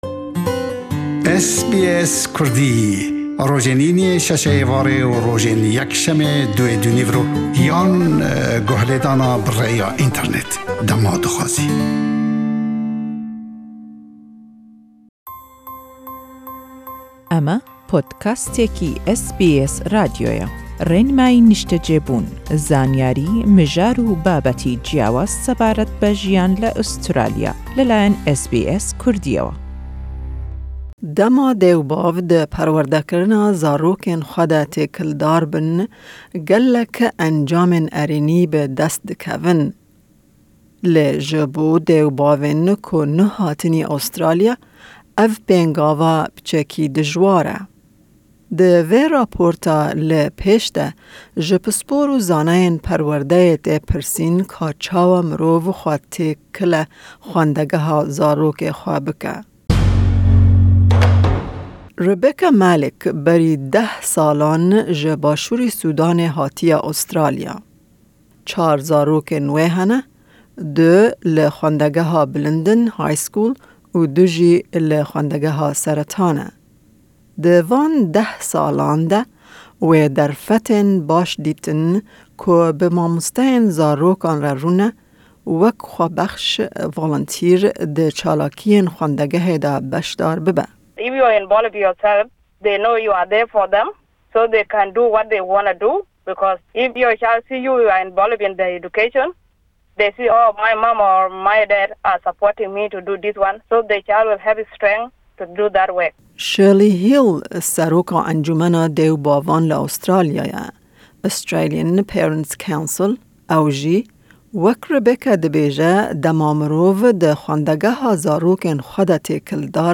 Di vê raporta li pêsh de ji pispor û zaneyên perwerdeyê tê pirsîn ka çawa mirov xwe têkile xwendegeha zarokê xwe bike tê kirin.